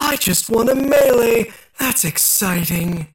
75 KB Viscous voice line (unfiltered) - I just won a melee!